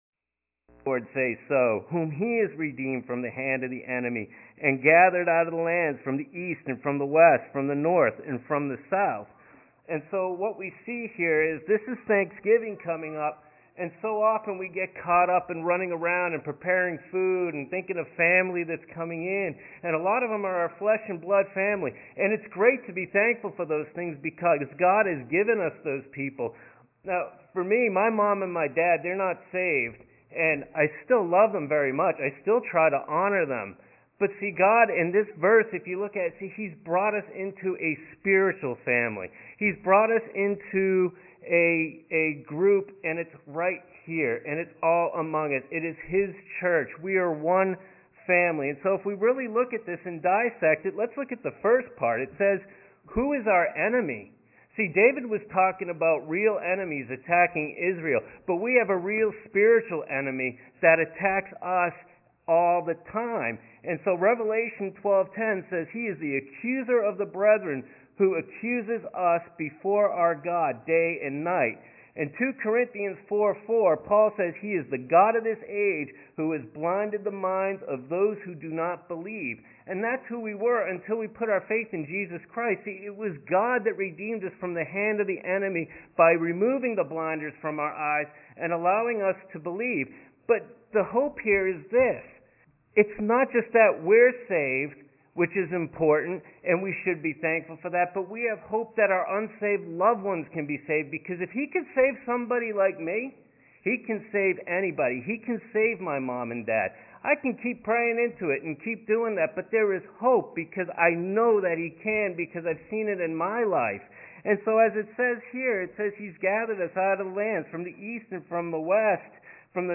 Passage: Psalm 107:1-31 Service Type: Sunday Morning